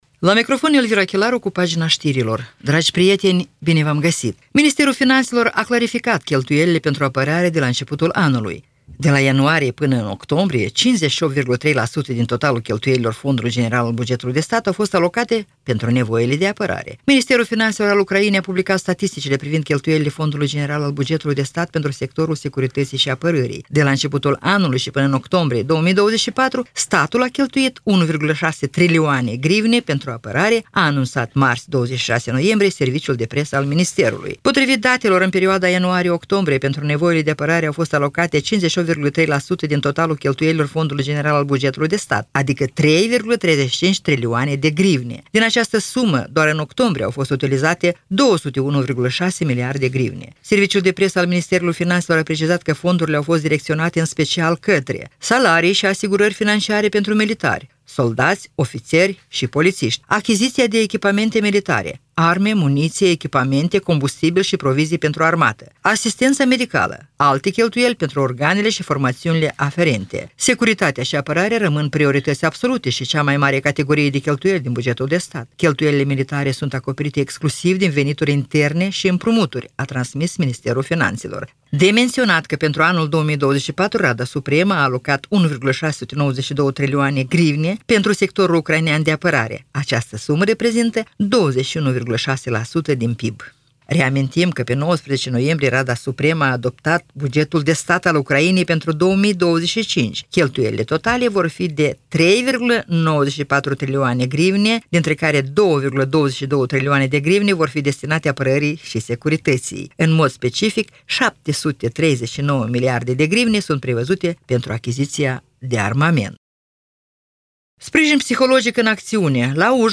Ştiri Radio Ujgorod – 27.11.2024